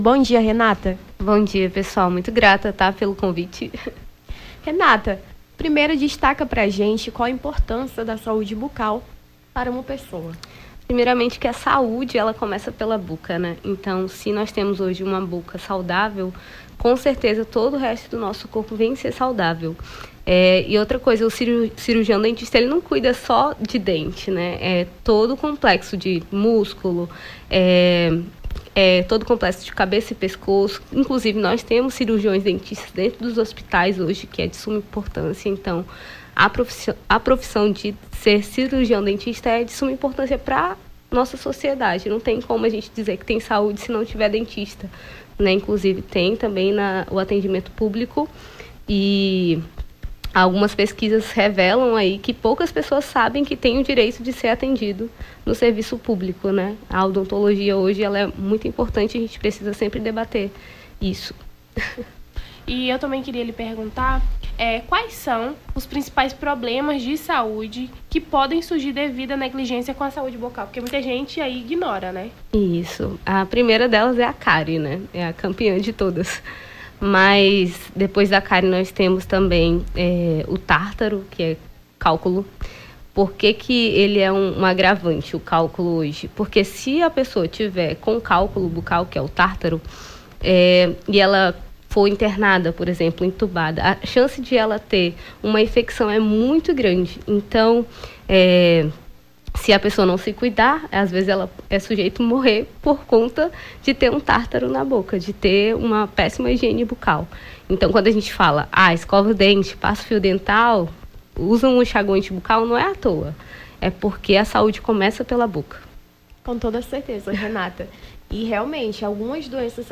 Nome do Artista - CENSURA - ENTREVISTA (DIA MUNDIAL DO DENTISTA) 03-10-23.mp3